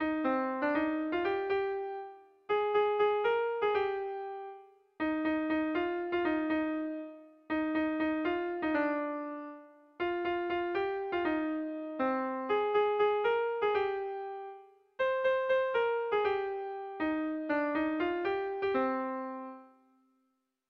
Sentimenduzkoa
Zortziko txikia (hg) / Lau puntuko txikia (ip)
ABDE